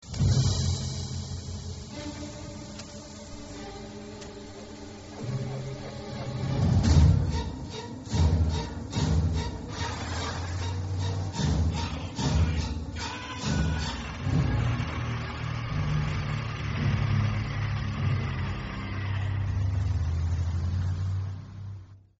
classic psycho strings